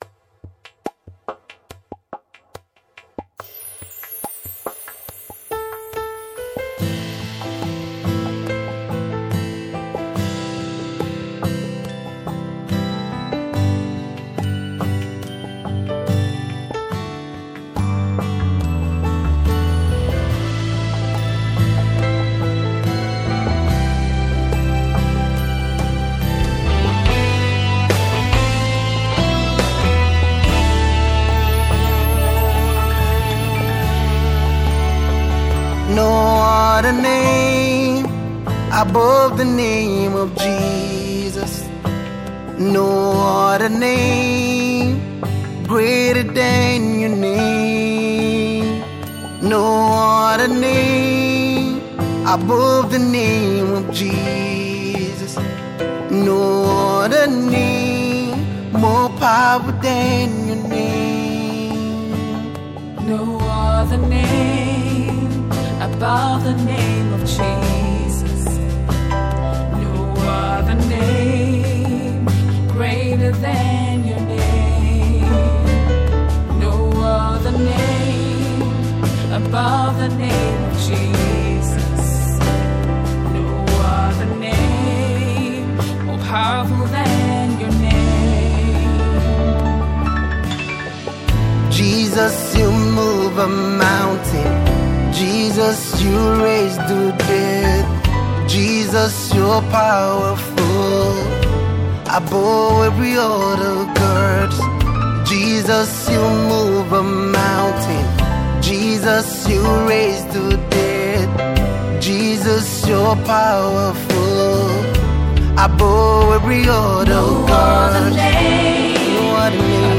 a Nigerian gospel singer songwriter